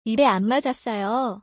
イベ アンマジャッソヨ